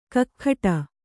♪ kakkhaṭa